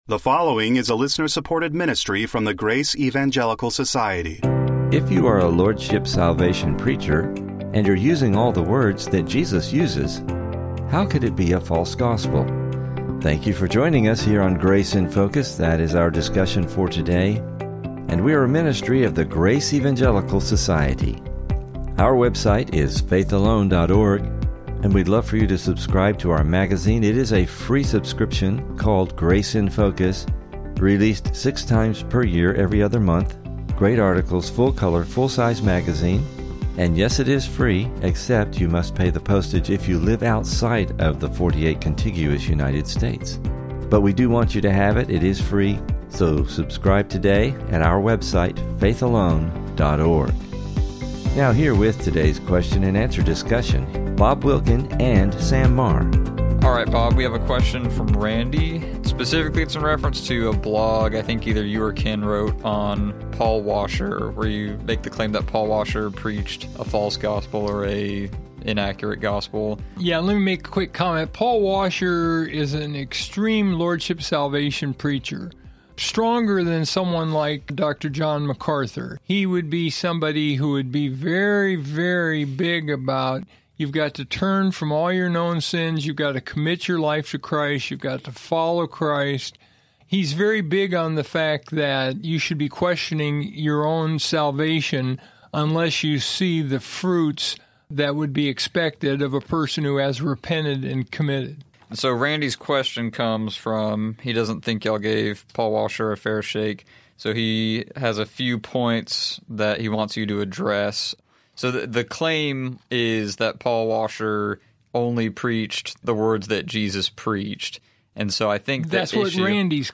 Please listen for a great discussion, and never miss an episode of the Grace in Focus Podcast!